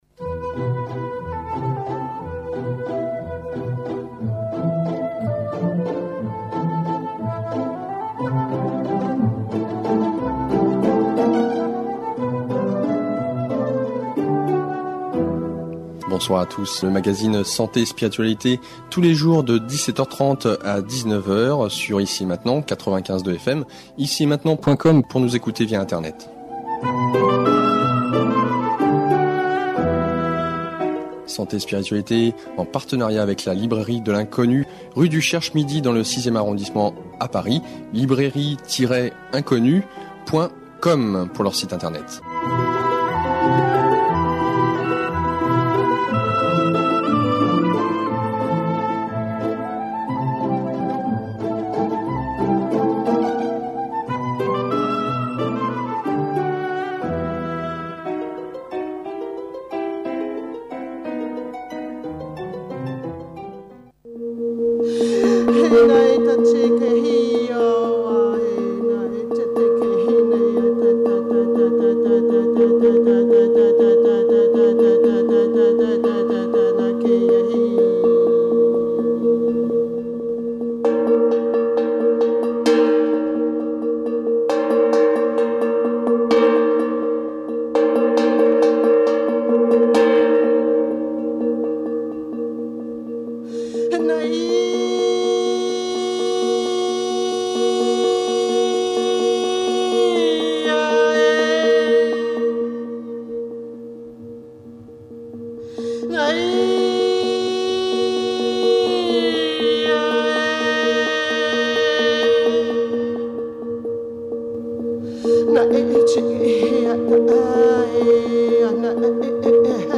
Interview sur Radio Ici & Maintenant :La Radio Ici & Maintenant est une radio associative qui repose uniquement sur les donations des auditeurs.